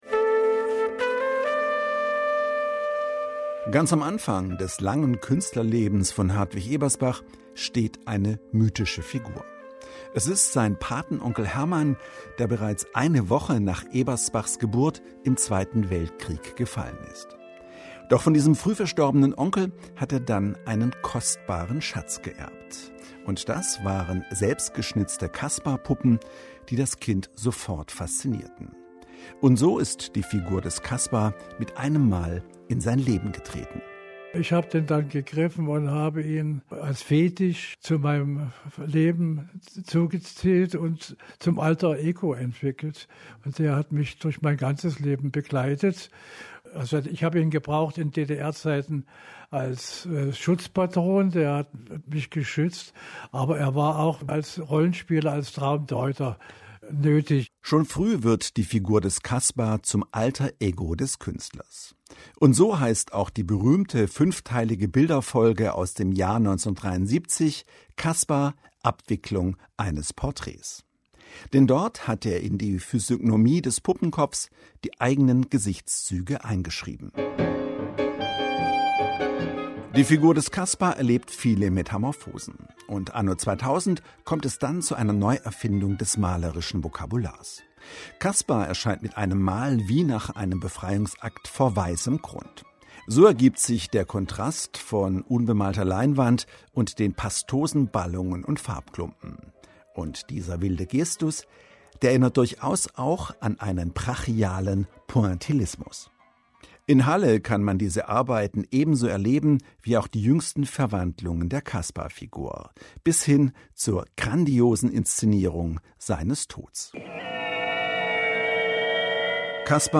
MDR Kultur | Radiobeitrag